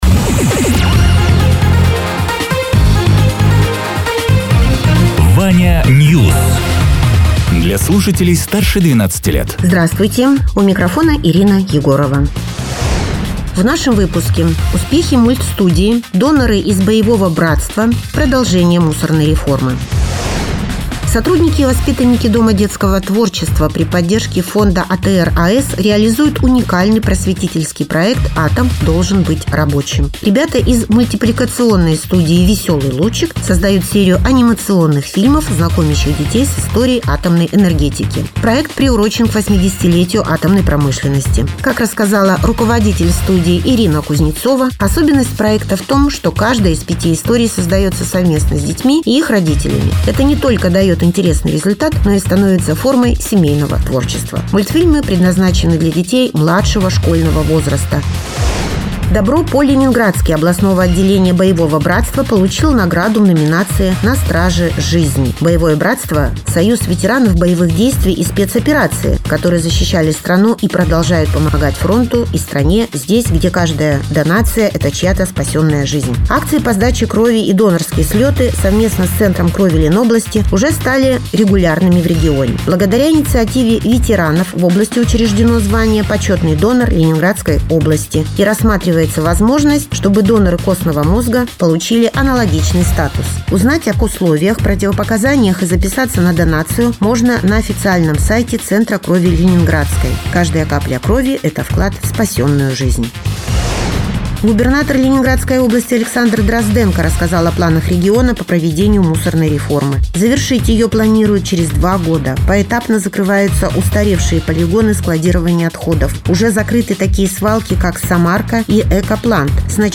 Радио ТЕРА 08.12.2025_12.00_Новости_Соснового_Бора